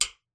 metronomehigh.wav